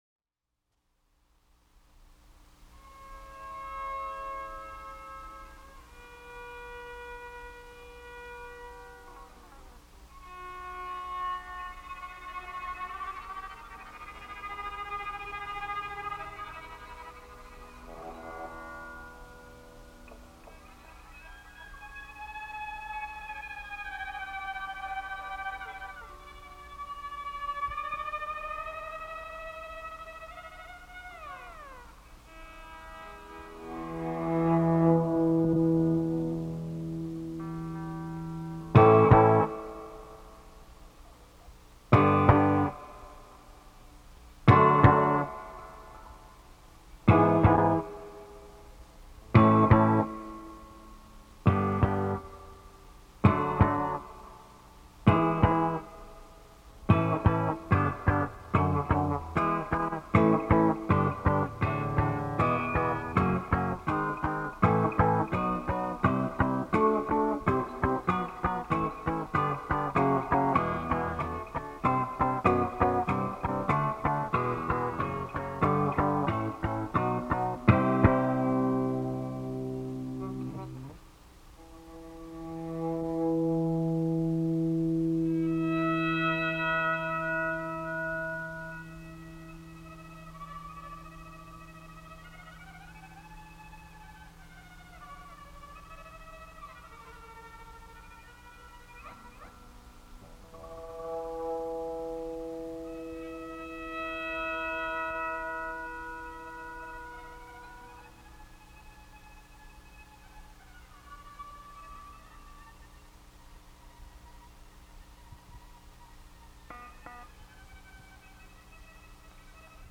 Gibson Les Paul guitar, vocals